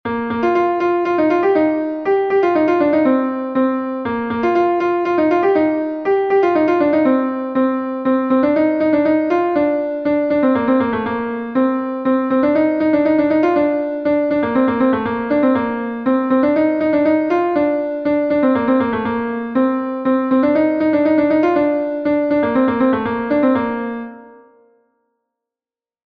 Gavotenn Pleurdud est un Gavotte de Bretagne